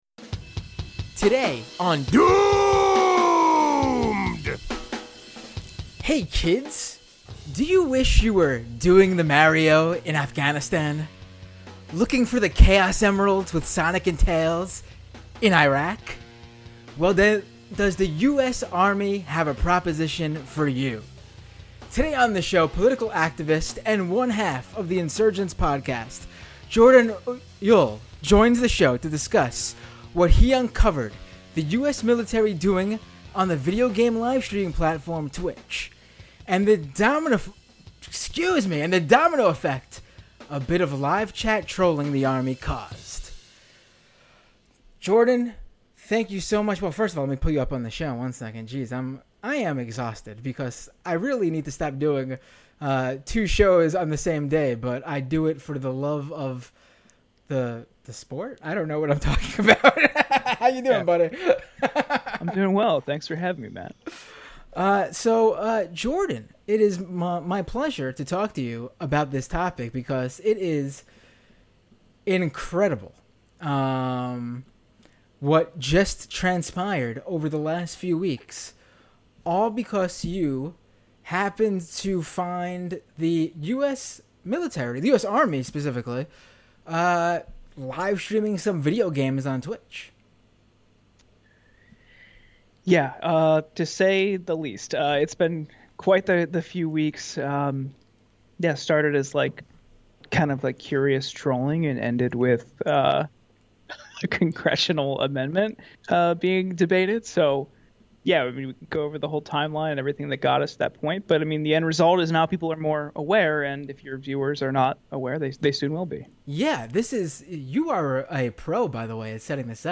(Episode from the 10/28/21 livestream show.)